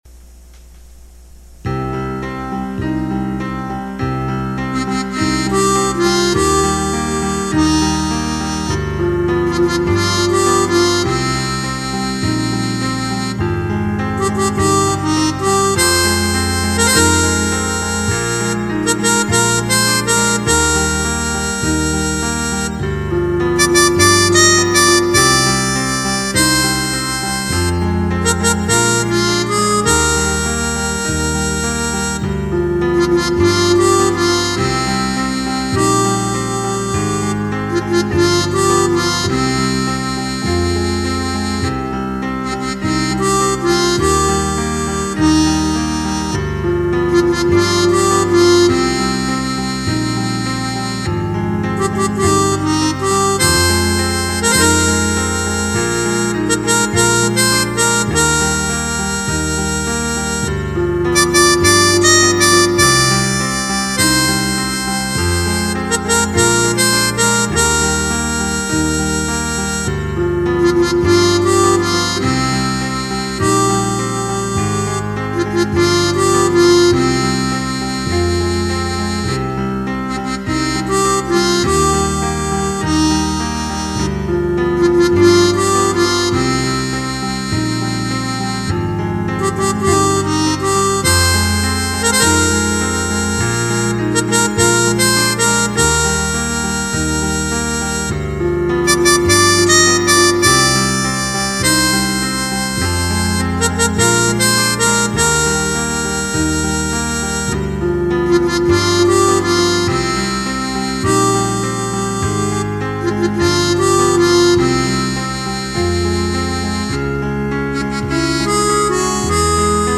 Le TRK est le fichier midi en format mp3 sans la mélodie.